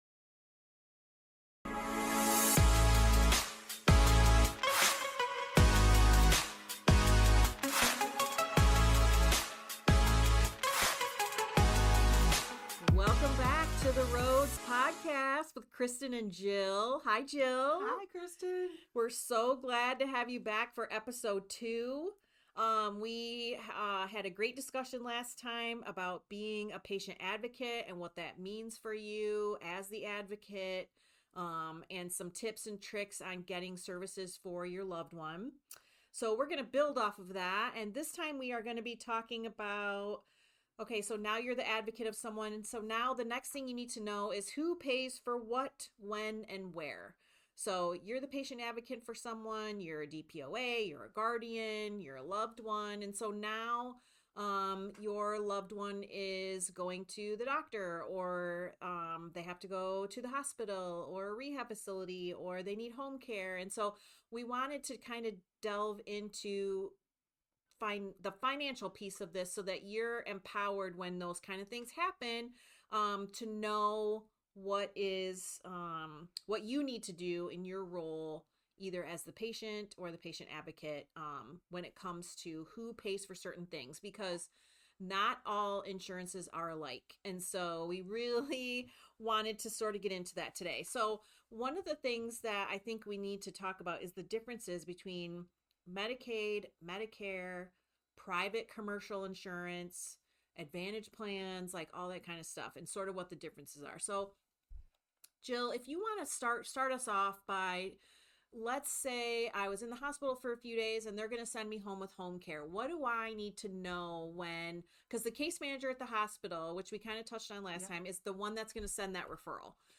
The biggest lesson from this conversation is that understanding coverage ahead of time helps families make better care decisions with fewer surprises.